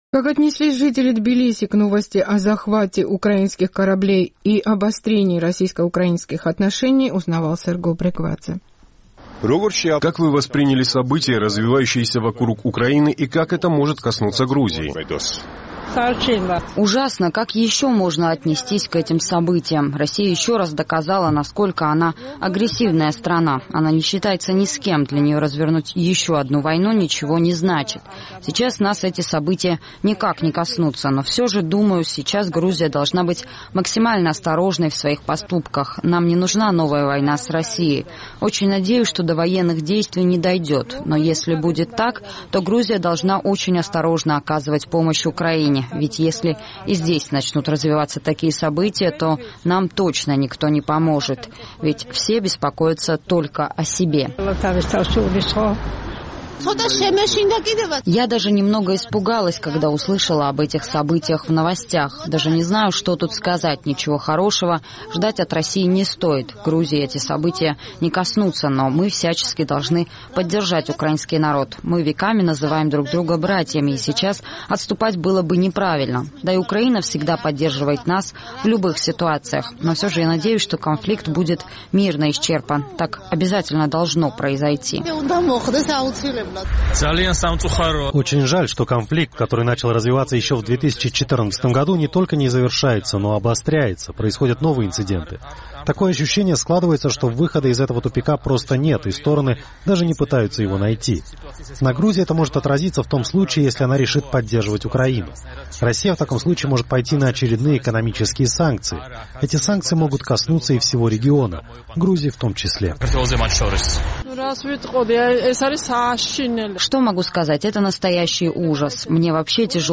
Как отнеслись жители грузинской столицы к новости о захвате украинских кораблей, узнавал наш тбилисский корреспондент.